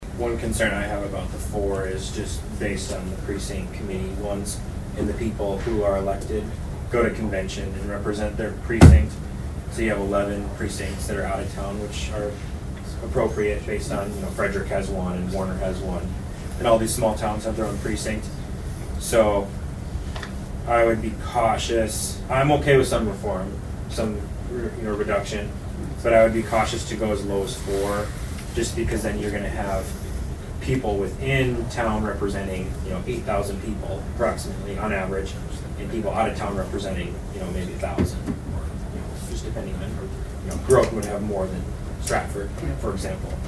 ABERDEEN, S.D.(HubCityRadio)- At Tuesday’s Brown County Commission meeting, the commissioners received a report from Brown County Auditor Lyn Heupel talking about the number of precincts within the city of Aberdeen.